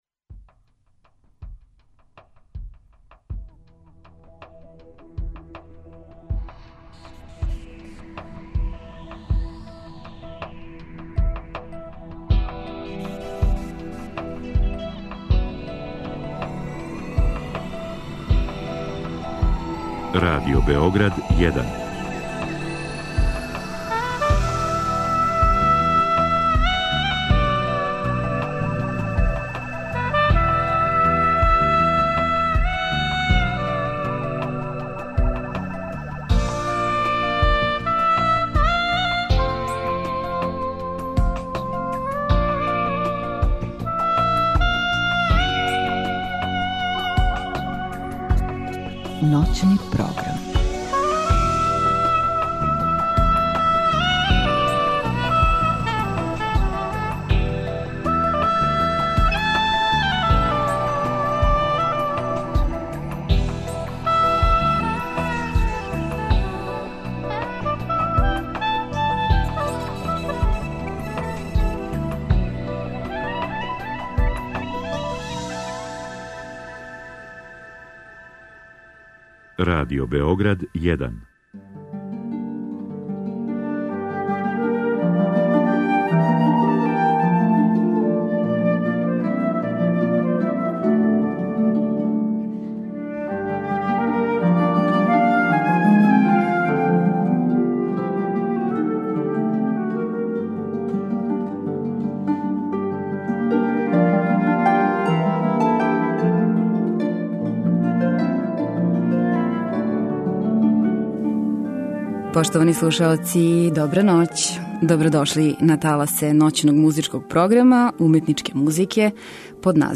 И још много тога уз музику Шопена, Листа, Хендла, Менделсона, Дебисија, Дворжака и Брамса.